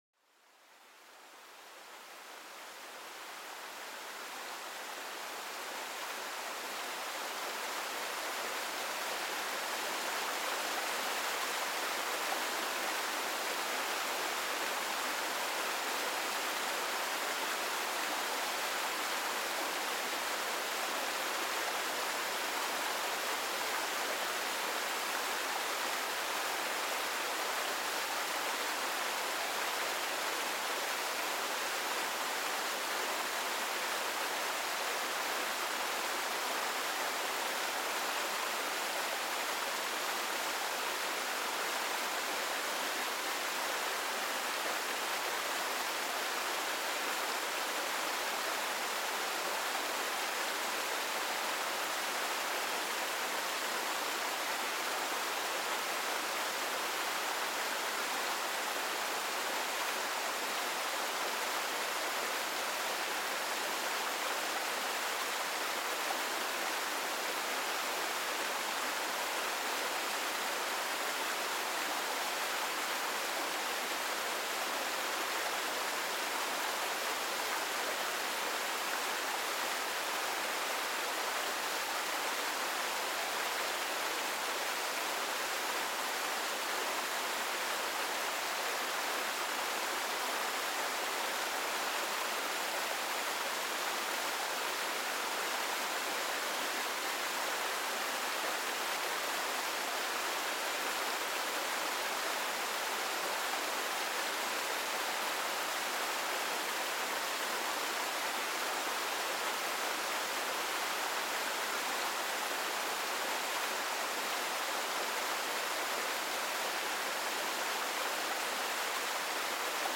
El relajante sonido de una CASCADA calma la mente y invita al sueño
Déjate envolver por el sonido relajante de una cascada que fluye suavemente en un entorno verde y exuberante. Este murmullo del agua, combinado con el suave canto de los pájaros, crea una atmósfera serena perfecta para calmar la mente y favorecer un sueño profundo.